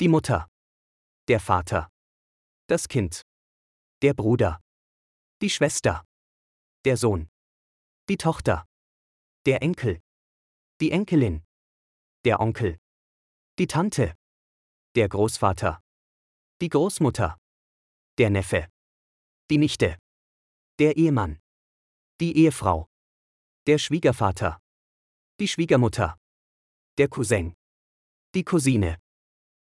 سنضع لكم لفظ كل كلمة مكتوبة بالعربي وايضاً صوتياً لأن كلمات المانية مكتوبة بالعربي تساعد المبتدئين في تعلم اللغة الألمانية بشكل أفضل وأسرع.